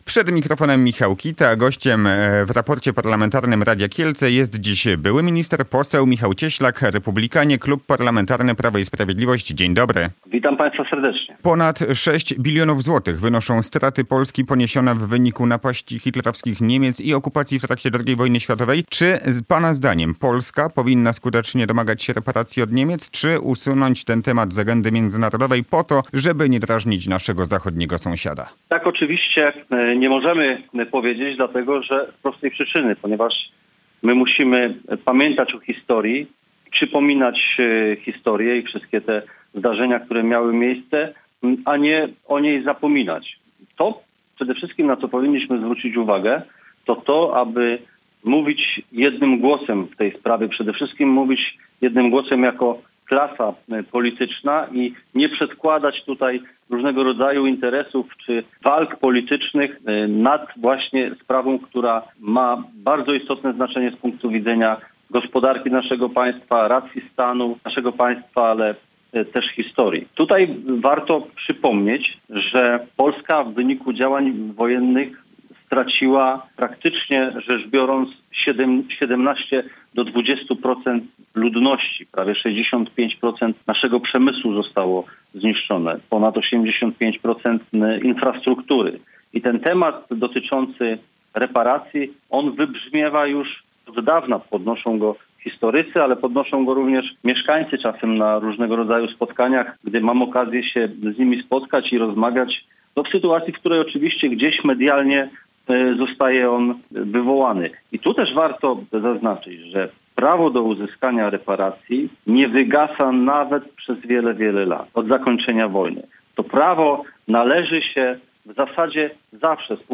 – Jedność całej klasy politycznej w sprawie reparacji powinna być Polską racją stanu. W tej kwestii musimy mówić jednym głosem i nie przenosić walk politycznych na sprawę, która jest niezwykle ważna z punktu widzenia gospodarki i historii – stwierdził w Raporcie Parlamentarnym na antenie Radia Kielce poseł Michał Cieślak wiceprezes partii Republikańskiej z klubu parlamentarnego Prawa i Sprawiedliwości.